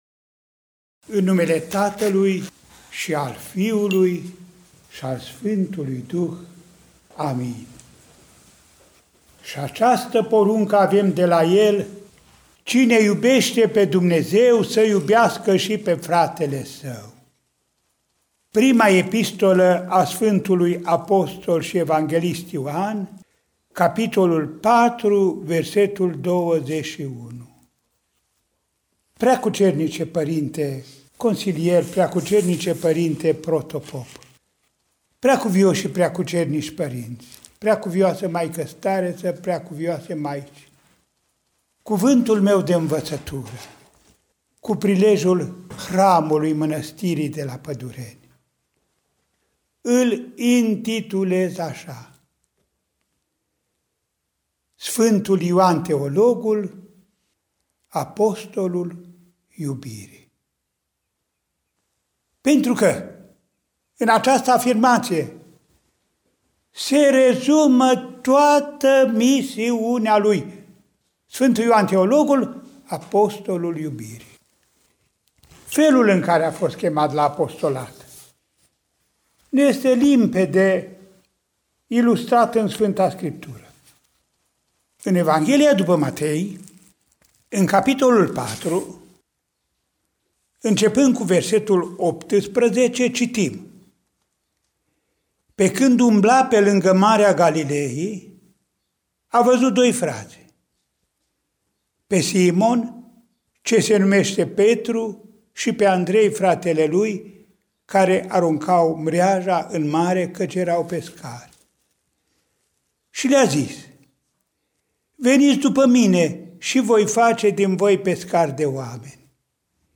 mai 8, 2020 | Predici IPS Andrei